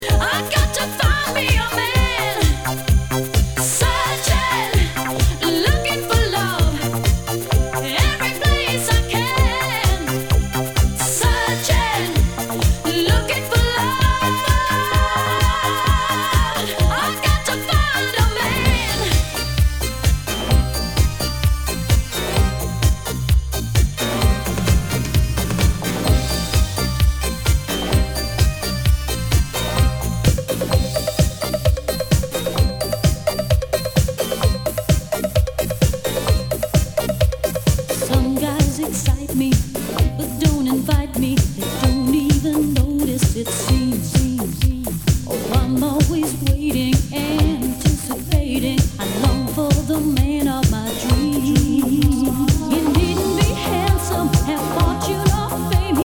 類別 歐陸舞曲
SOUL/FUNK/DISCO
ナイス！ハイエナジー / シンセ・ポップ・ディスコ！
全体にチリノイズが入ります